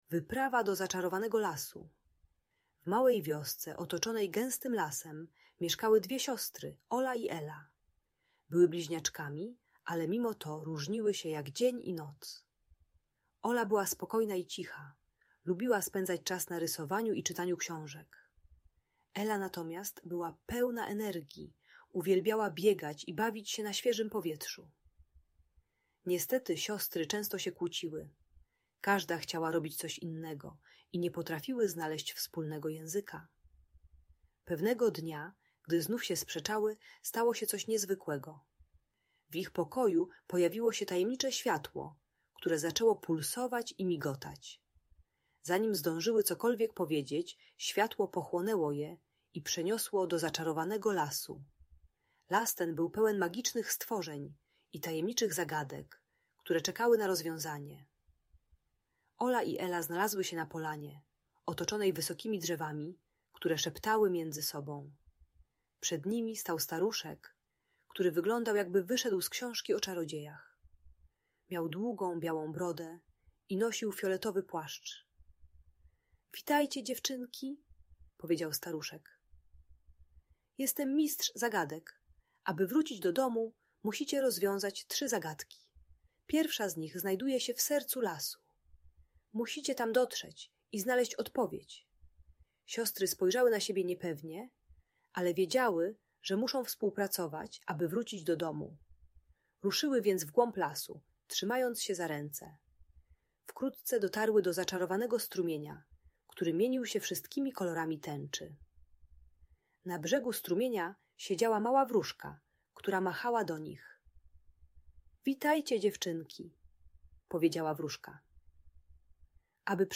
Wyprawa do Zaczarowanego Lasu - Rodzeństwo | Audiobajka